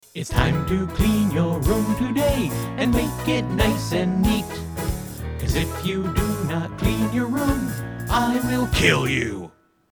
At this point I'd moved past the 4-track recorder and was recording everything digitally onto the PC.